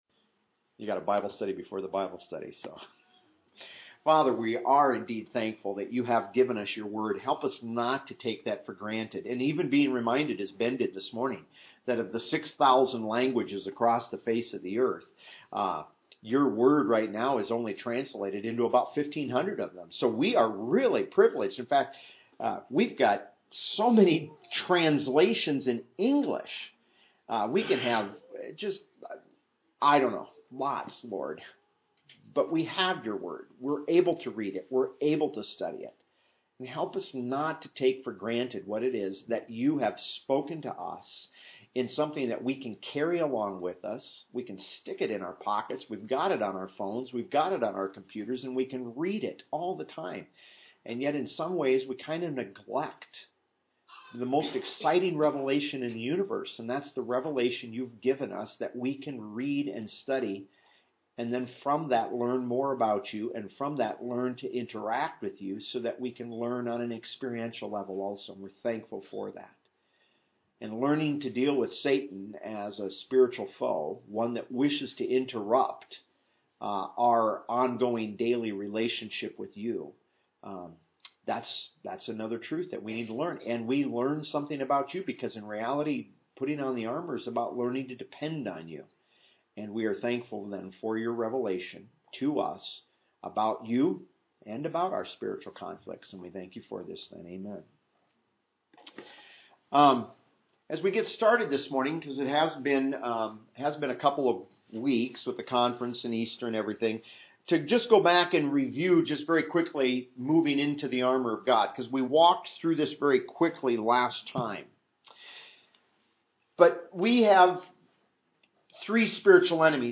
PLEASE NOTE: When you are listening to these audio files, keep in mind that you might hear the sound of children or of people asking questions during the teaching.
You will not find a strict, formal, religious ceremony atmosphere in these Bible teachings. Also note that due to technical difficulties, some earlier recordings might be more difficult to hear.